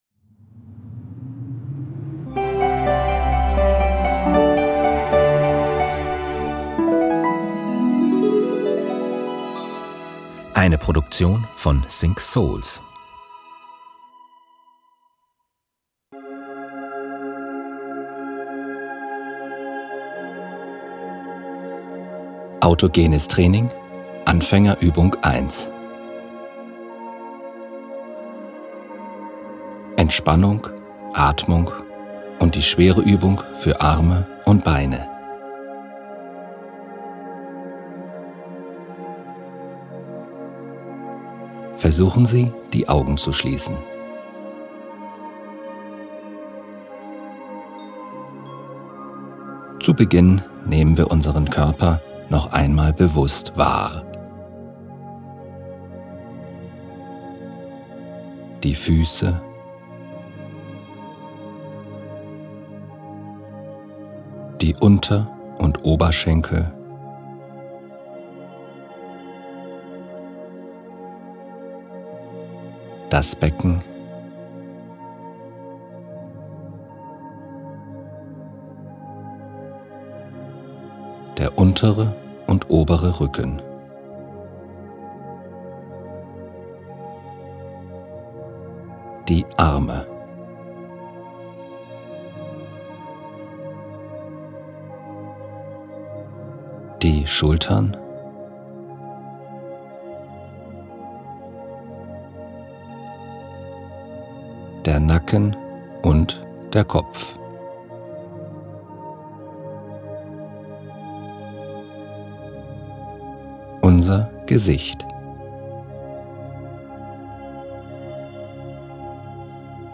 SyncSouls stellt Ihnen hier die komplette Übung 1 &Entspannung, Atmung und die Schwereübung für Arme und Beine& aus dem Hörbuch Autogenes Training 1 als freies MP3 zum Download zur Verfügung.